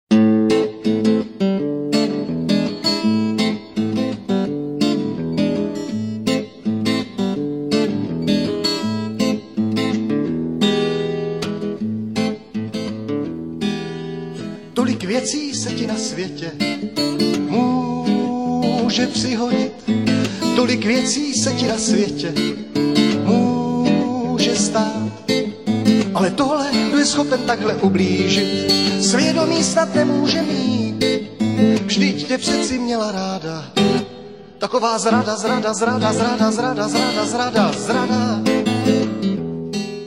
folkový písničkář původem z Ústí nad Orlicí.